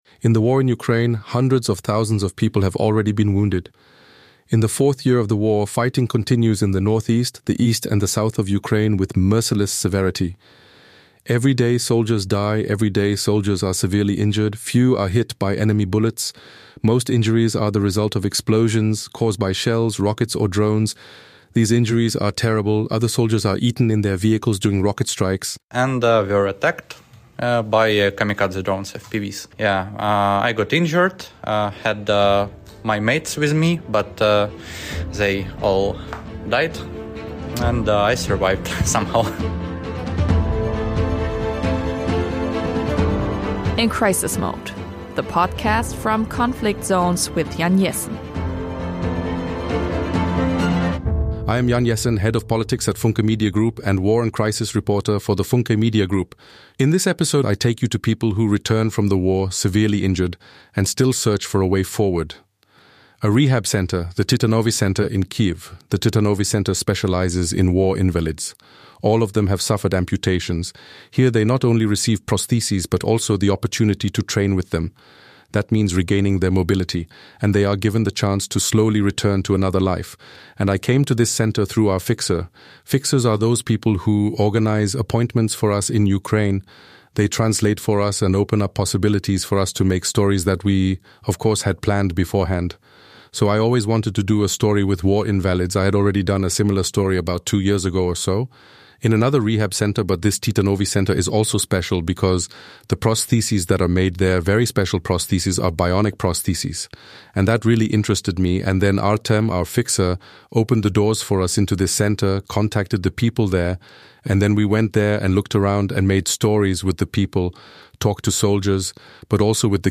Severely injured Ukrainians share how they survived attacks and explosions – and how they continue to live today with scars, pain, and bionic prosthetics.